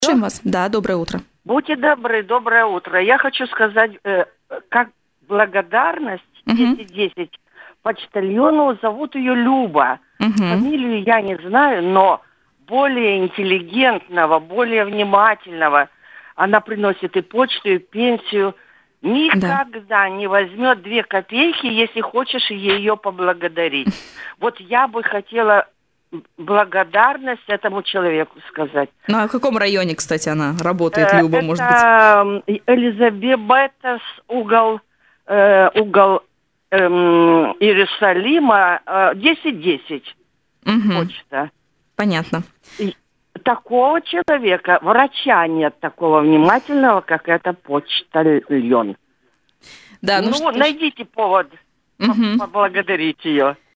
В «Утренний интерактив» на радио Baltkom позвонила слушательница